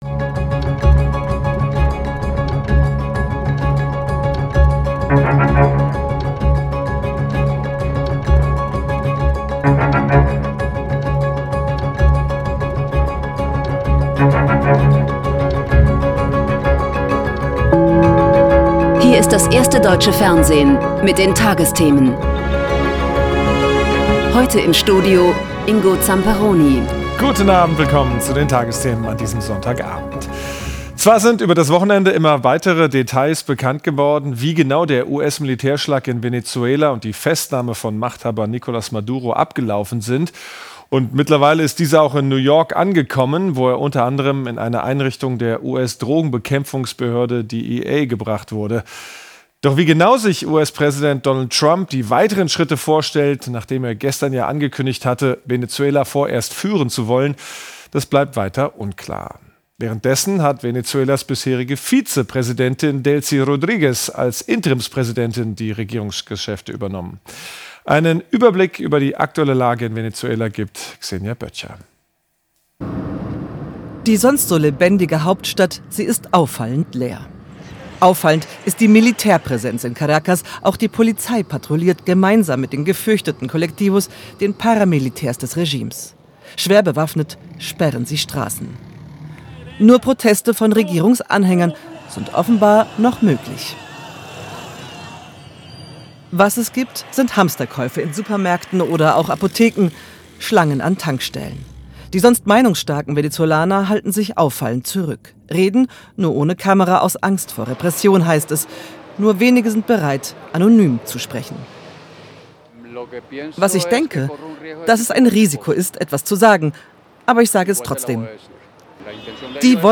… continue reading 2651 episodes # Tägliche Nachrichten # Nachrichten # Tagesschau